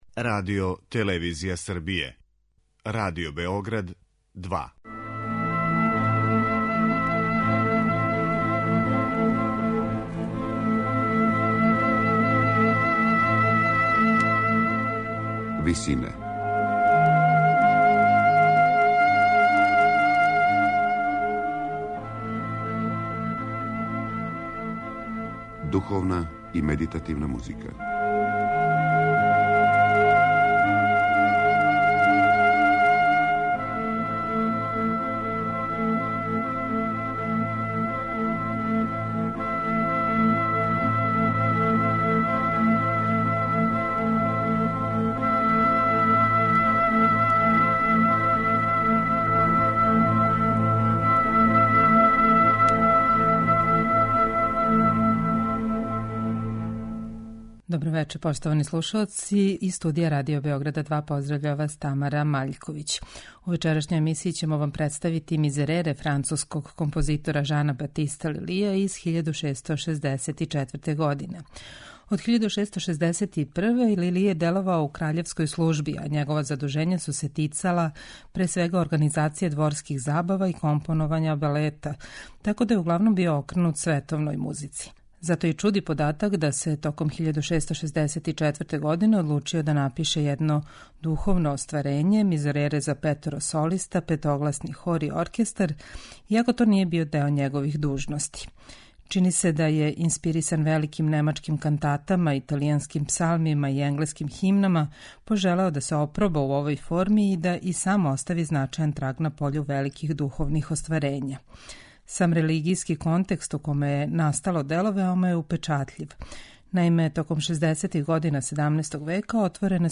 Емисија духовне и медитативне музике
сопрани
контратенор Алфред Делер
баритон